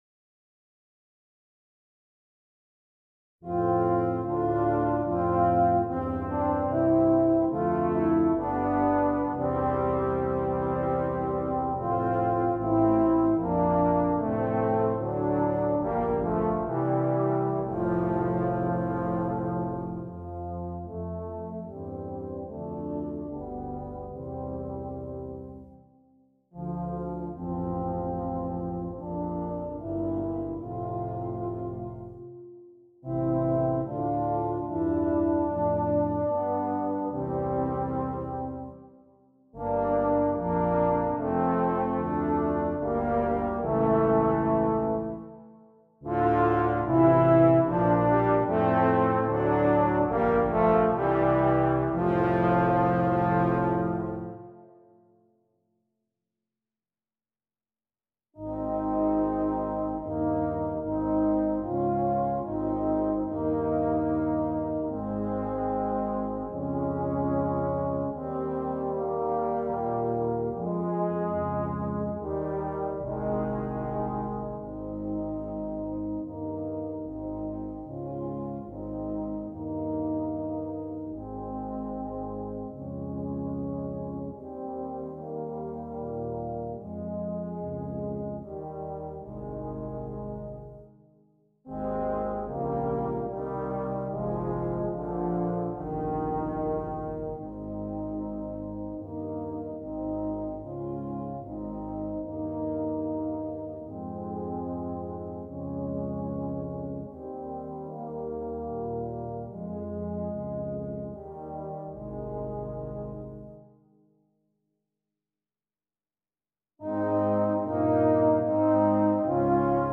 2 Euphonium, 2 Tuba